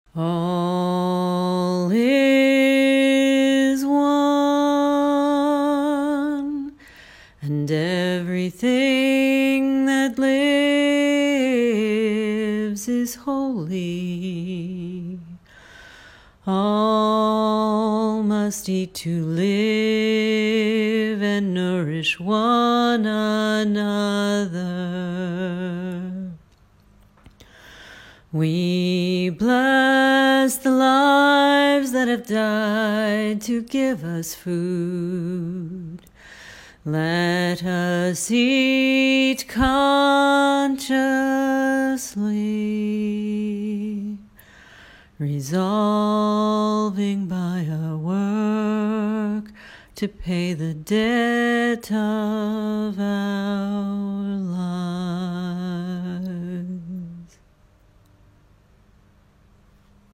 Chant: All is one, and everything that lives is holy Words from